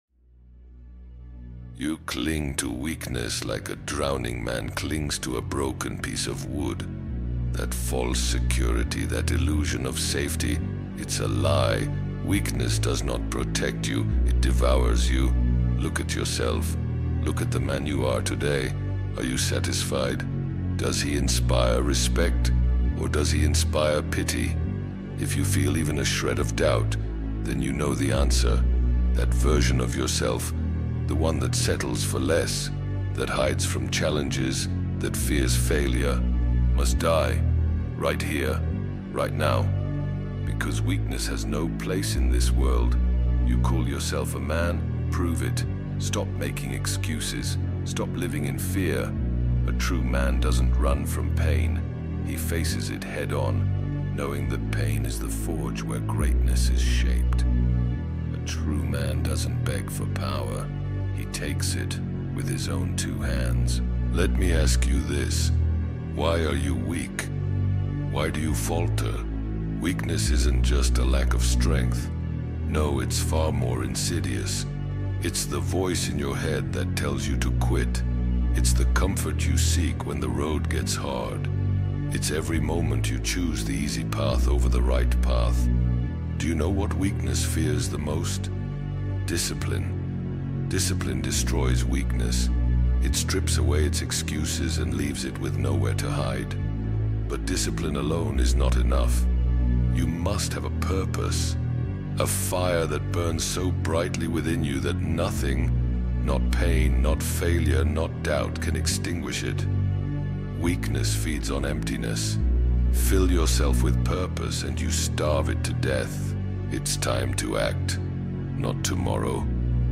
Destroy Your Weak Self | Anime Motivational Speech